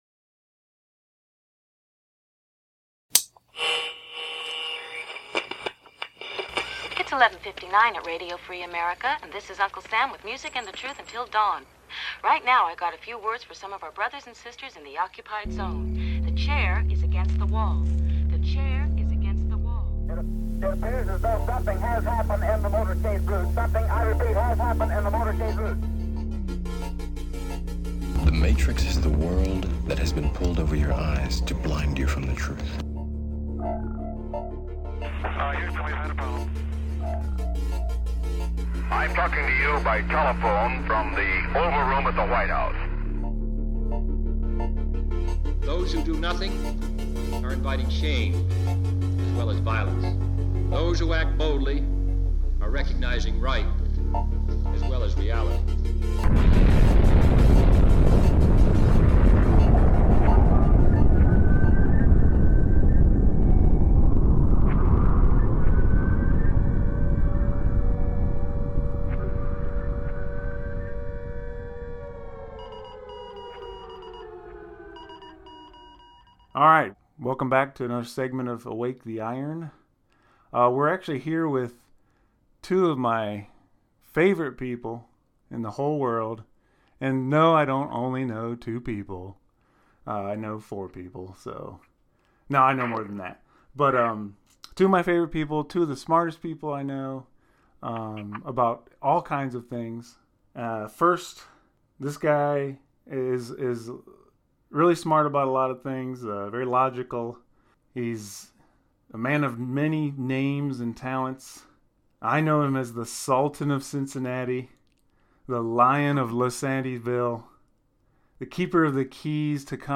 A lively discussion with two of my favorite people about the crazy clown show that was 2020... and more.